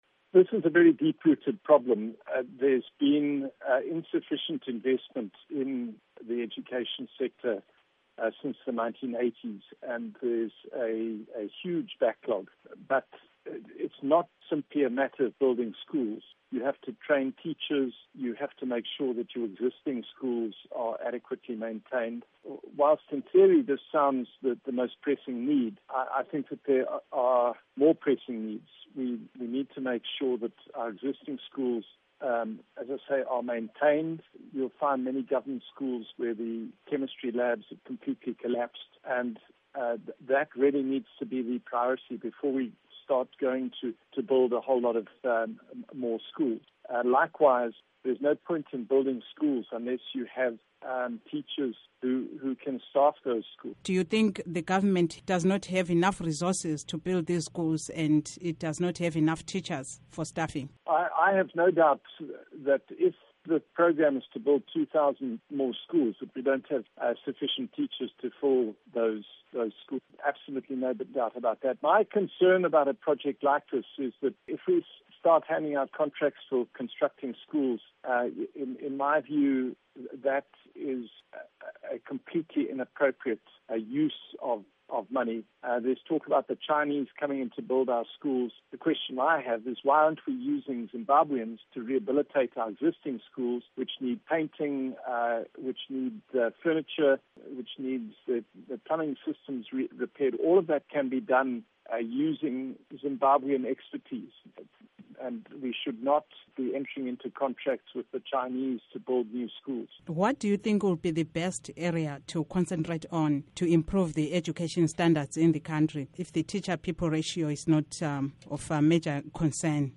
Interview with David Coltart